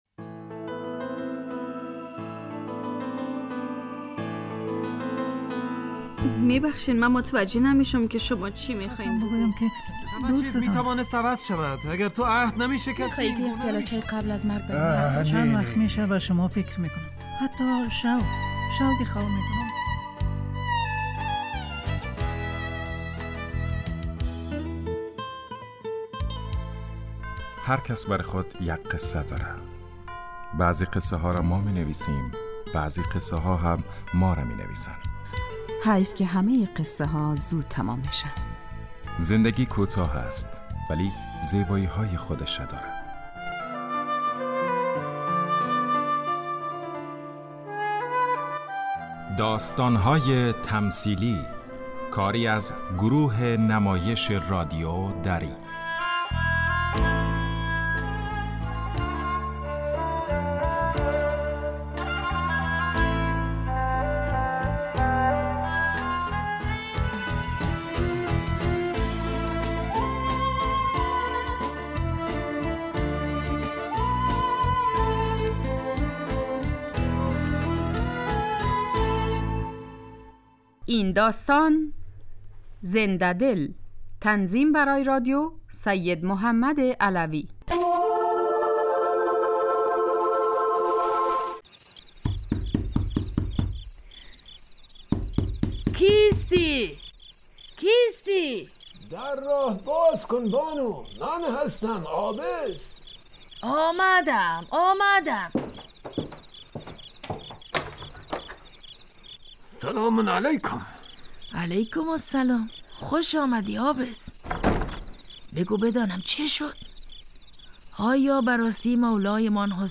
داستان تمثیلی / زنده دل